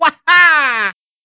18_luigi_waha.aiff